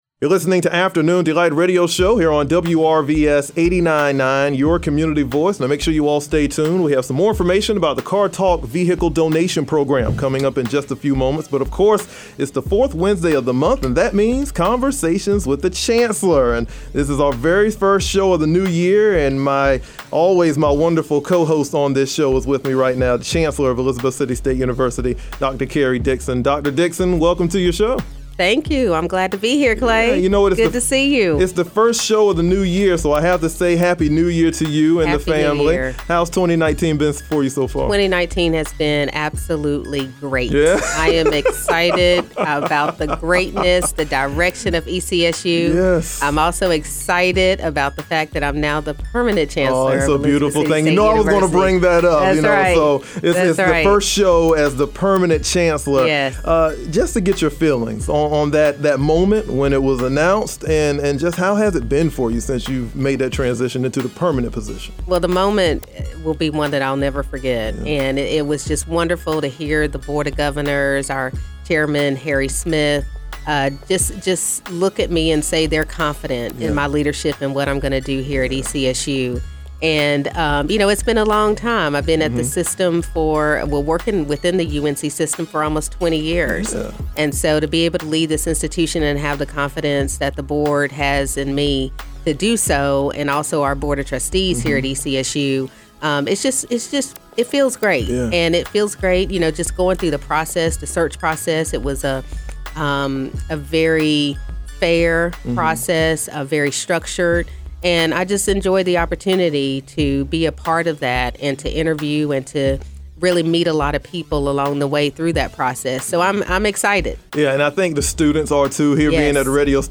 Conversations with the Chancellor airs every third Wednesday of the month on WRVS FM 89.9. On Wednesday